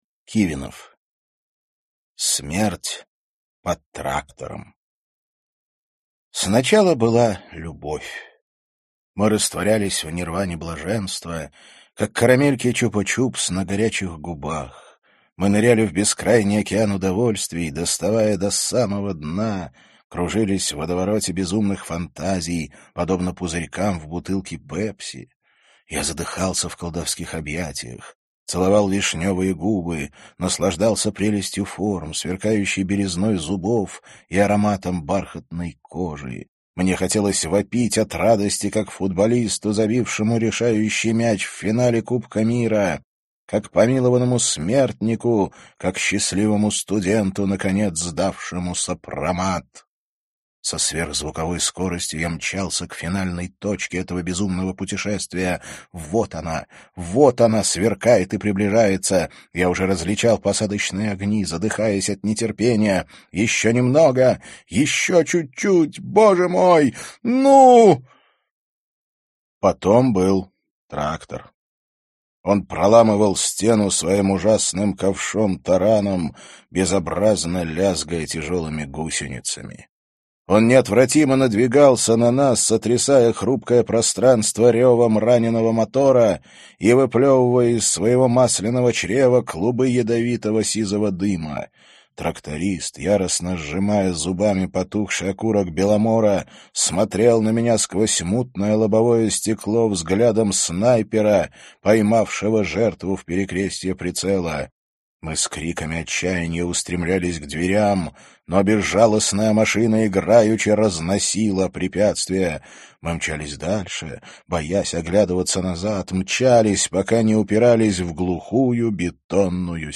Аудиокнига Смерть под трактором | Библиотека аудиокниг